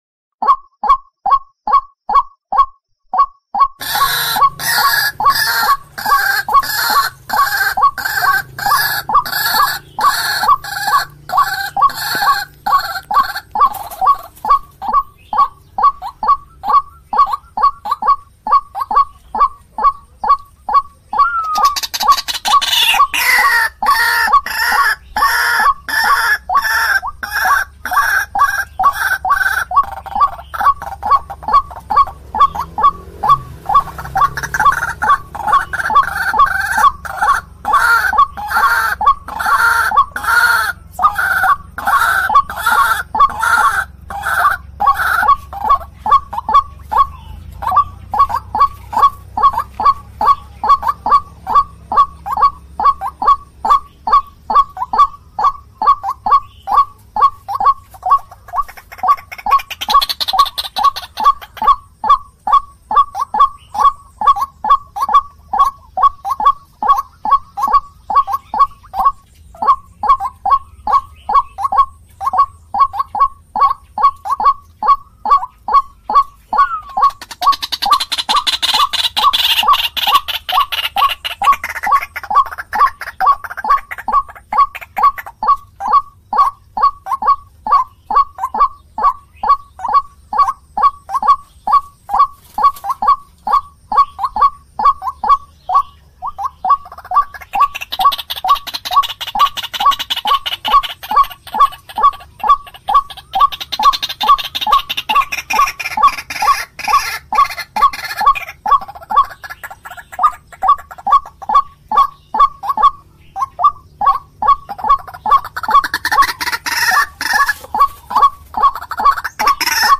Download sekarang dan nikmati keindahan alam melalui suara ruak-ruak yang menenangkan!
Suara Burung Ruak-Ruak Jernih Durasi Panjang
Kategori: Suara burung
Tag: suara Kareo Padi/ Ruak-ruak suara ladang/ rawa
suara-burung-ruak-ruak-jernih-durasi-panjang-id-www_tiengdong_com.mp3